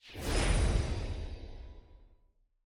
sfx-loot-reveal-acknowledge.ogg